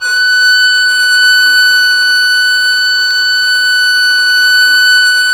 Index of /90_sSampleCDs/Roland - String Master Series/STR_Violin 4 nv/STR_Vln4 _ marc